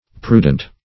Prudent \Pru"dent\, a. [L. prudens, -entis, contr. from